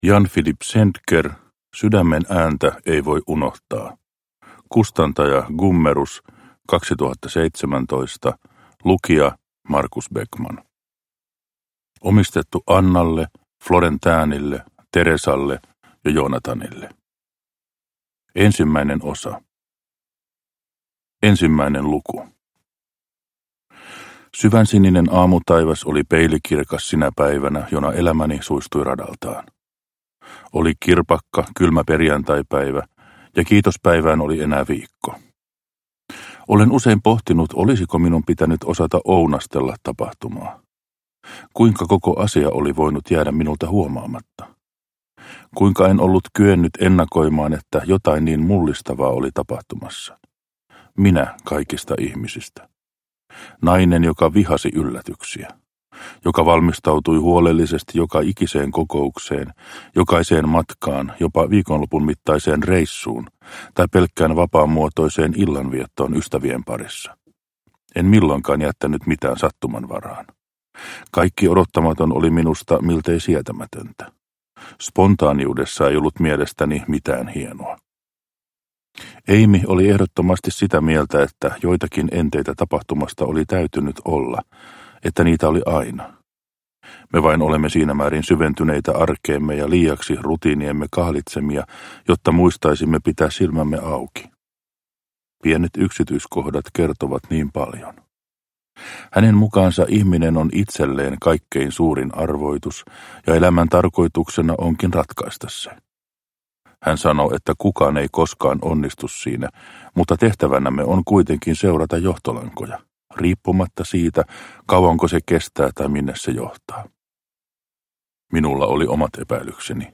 Sydämen ääntä ei voi unohtaa – Ljudbok – Laddas ner